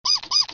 SFX小老鼠叫声的音效下载
这是一个免费素材，欢迎下载；音效素材为小老鼠叫声的音效， 格式为 mp3，大小1 MB，源文件无水印干扰，欢迎使用国外素材网。